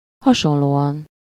Ääntäminen
Synonyymit pair similaire semblable identique synonyme pendant Ääntäminen France: IPA: [pa.ʁɛj] Haettu sana löytyi näillä lähdekielillä: ranska Käännös Ääninäyte 1. hasonlóan 2. viszont kívánom Suku: m .